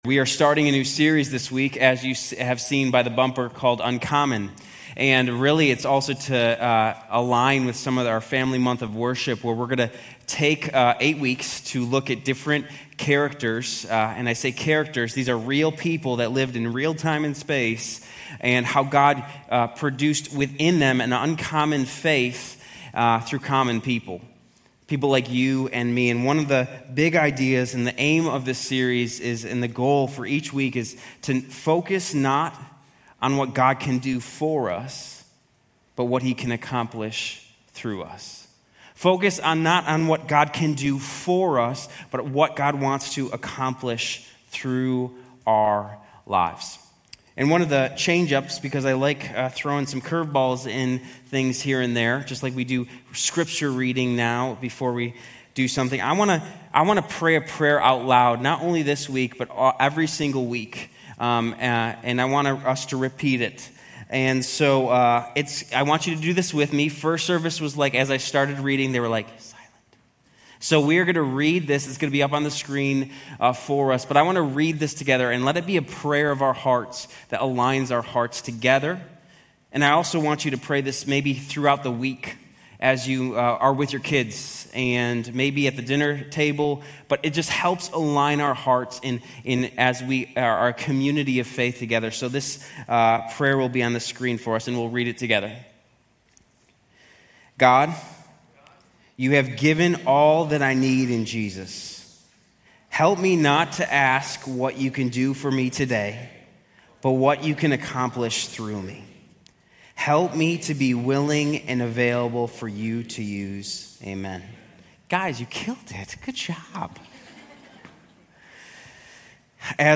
Grace Community Church University Blvd Campus Sermons 6_22 University Blvd Campus Jun 22 2025 | 00:26:29 1x 00:00 / 00:26:29 Subscribe Share RSS Feed Share Link Embed